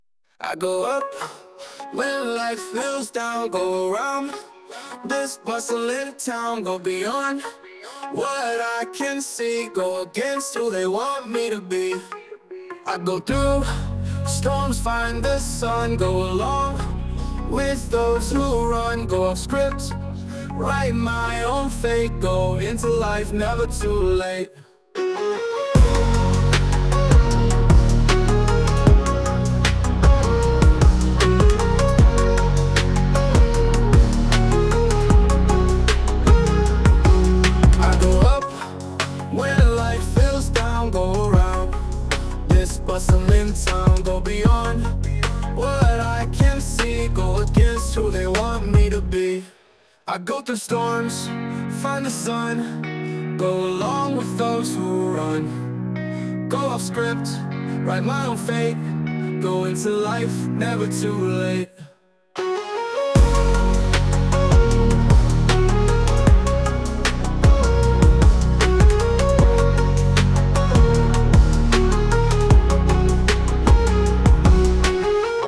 Мой R&B (я правда взялась за фразовые с GO, но принцип тот же):